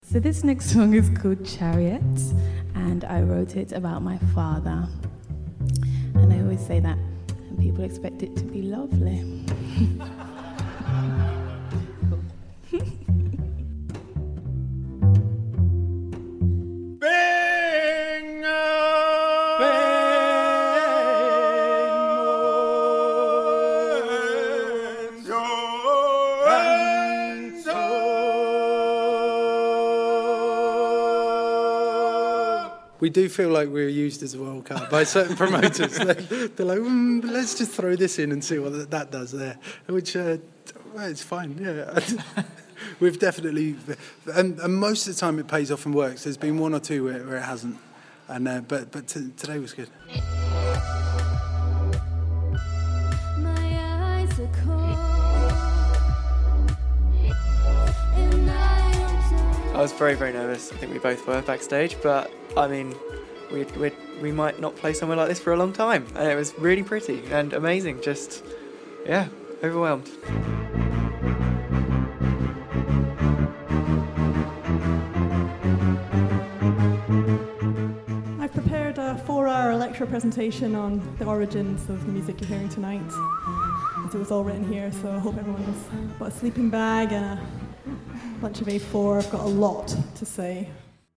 A preview of BBC Introducing in Suffolk's forthcoming podcast, featuring extended highlights from a recent night of new music in the Britten Studio at Snape Maltings.